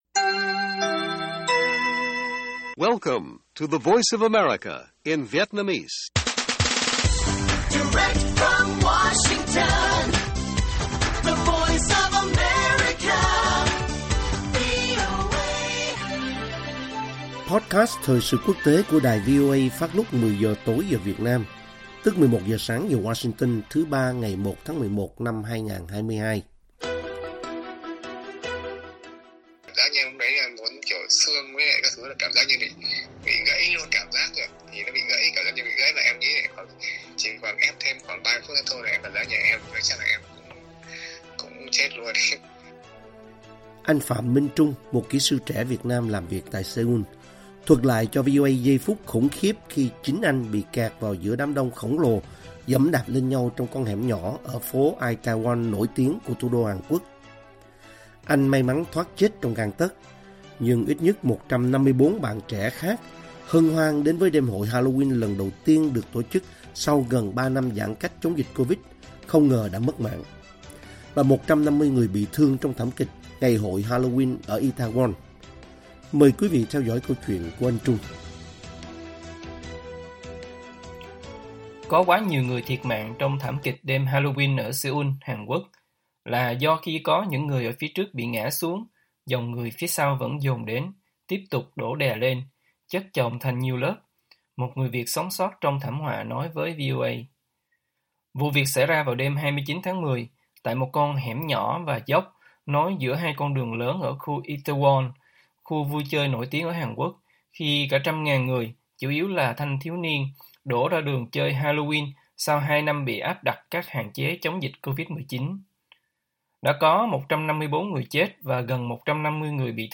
Thảm kịch Itaewon: Nhân chứng Việt sống sót kể lại ‘người chất chồng lên nhau’